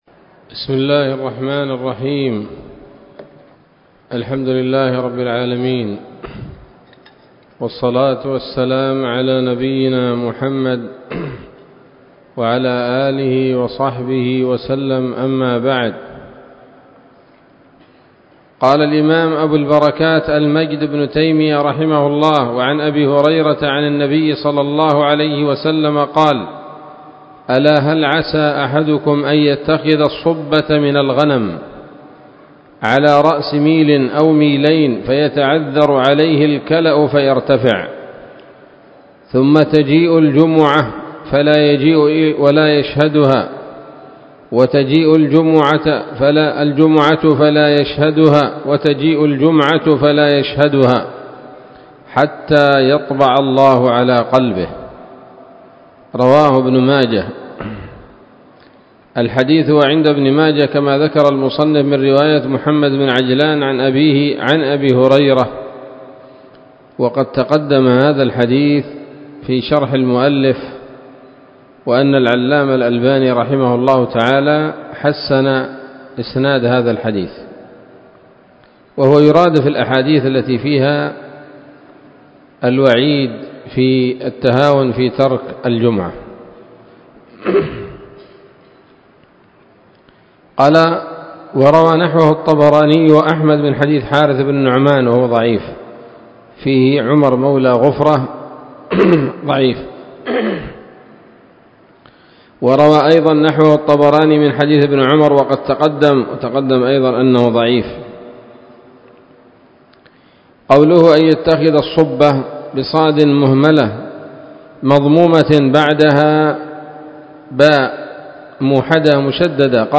الدرس الخامس من ‌‌‌‌أَبْوَاب الجمعة من نيل الأوطار